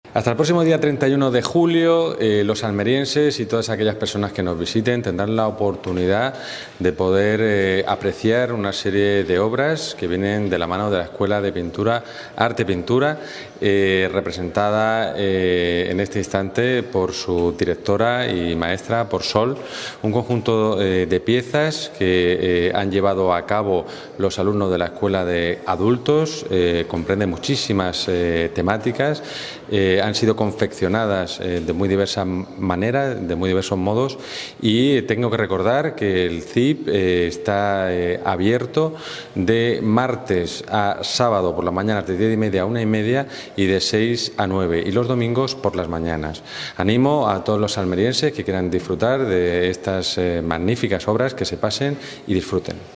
JOAQUIN-PEREZ-DE-LA-BLANCA-INAUGURACION-EXPOSICION-ARTEPINTURA.mp3